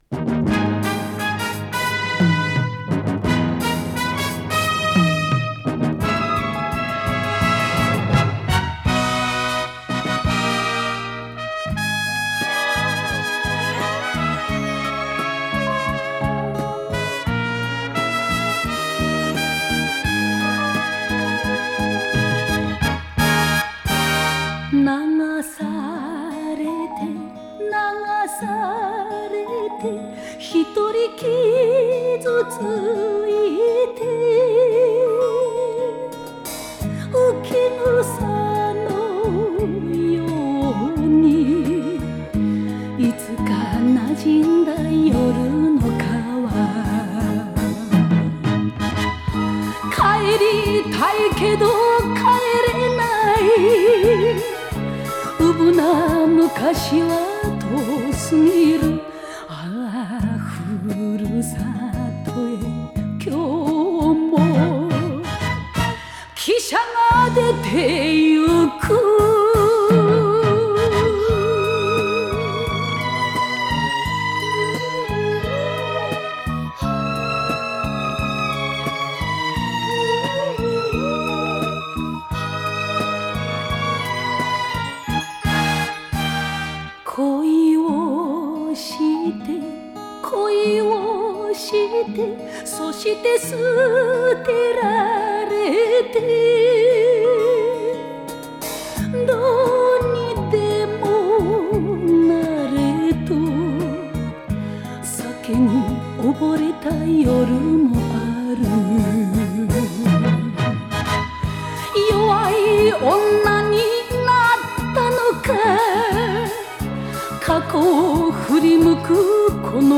Жанр: Enka